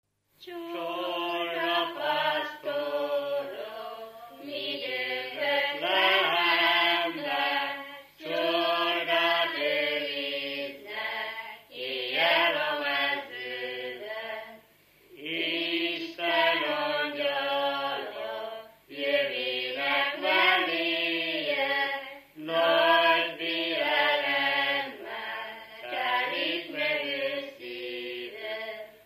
Dunántúl - Győr vm. - Gyömöre
Műfaj: Népének
Stílus: 7. Régies kisambitusú dallamok